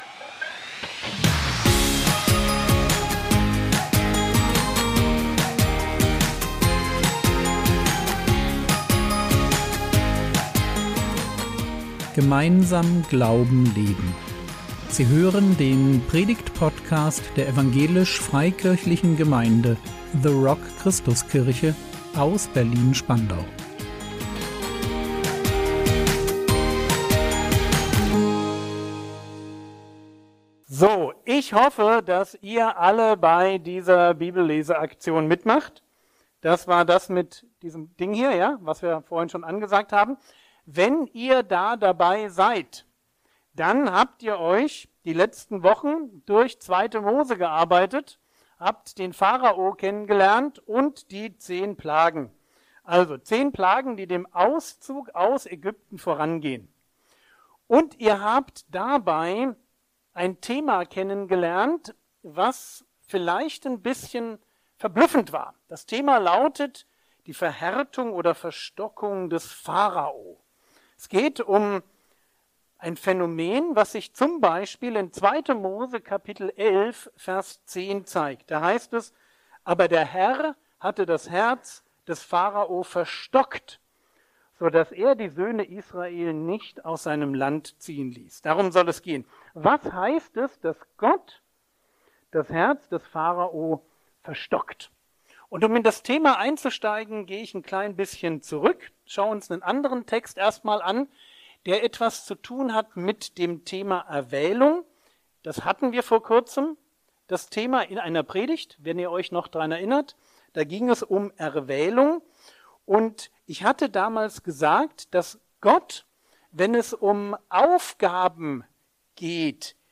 Die Verhärtung des Pharao | 16.03.2025 ~ Predigt Podcast der EFG The Rock Christuskirche Berlin Podcast